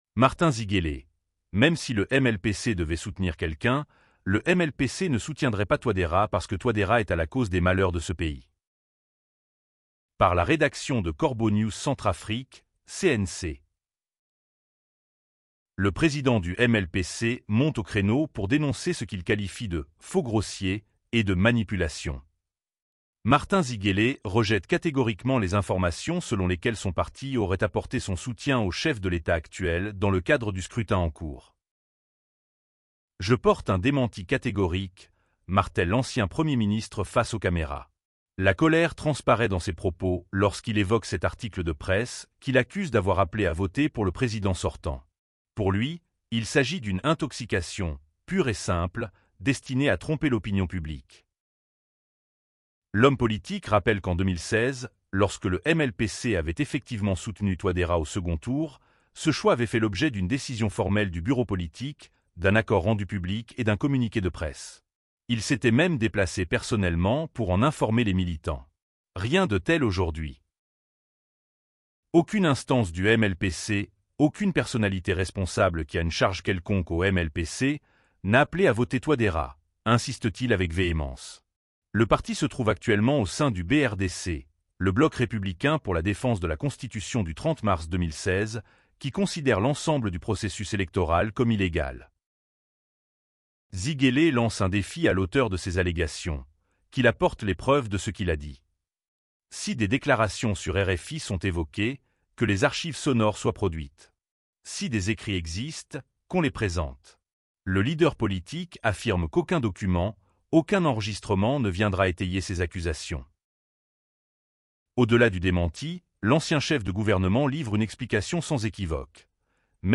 La colère transparaît dans ses propos lorsqu’il évoque cet article de presse qui l’accuse d’avoir appelé à voter pour le président sortant.